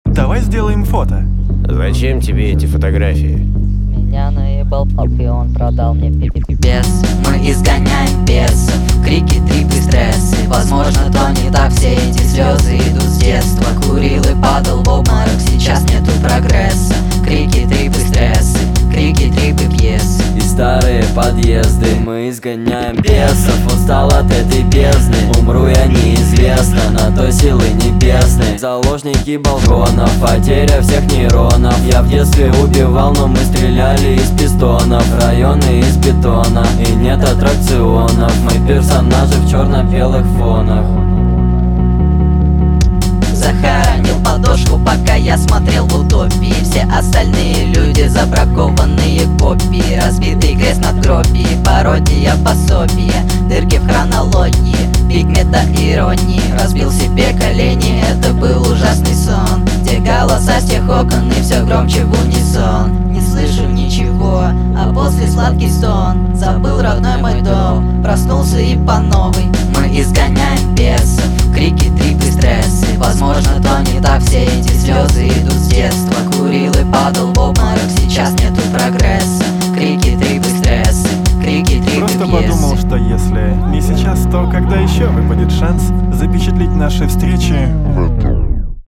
Рэп, Хип-хоп